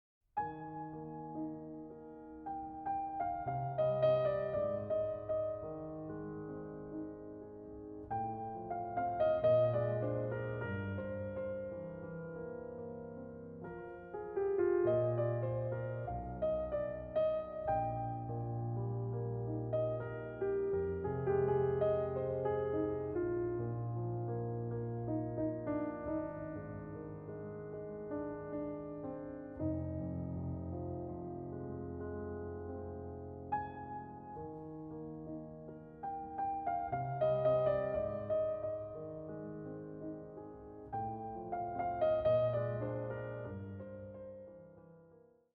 これらの「聴きやすい」曲にはクライマックスや終着点が無く、宙に浮くように美しい叙情性だけがいつまでも残っていきます。